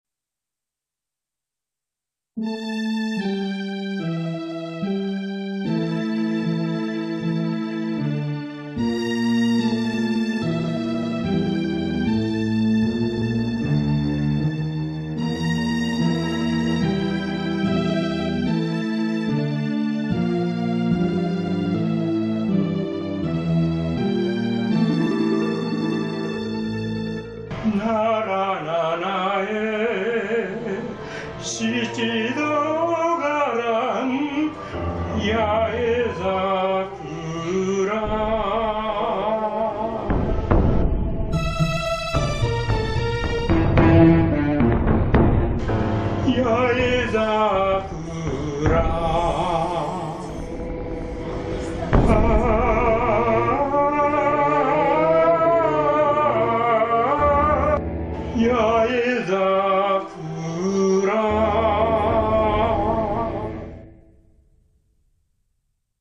俳句ですから、とても短い曲になるのは避けられず、「八重桜」の
部分を何度か繰り返して歌うことにしました。
伴奏に使った楽器は、Roland Sound Canvas SC-88 という製品です。